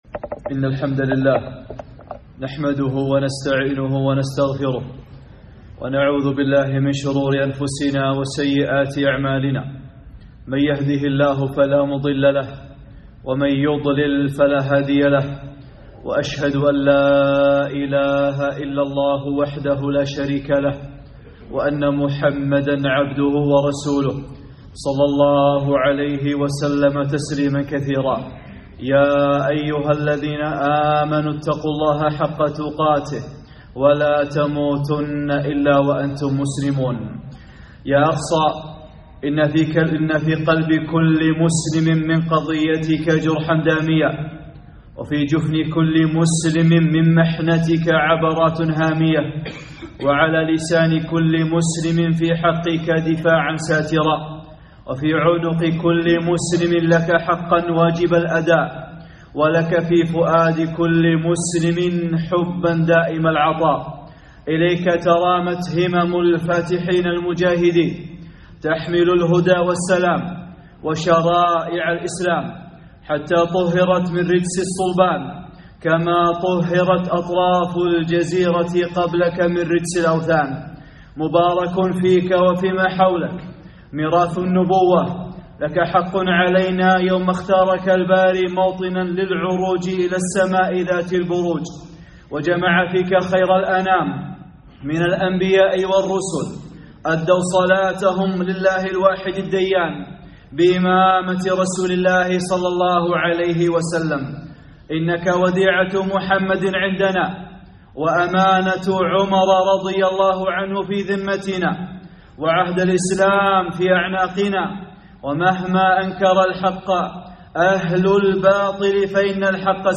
(١) خطبة - حقائق حول المسجد الأقصى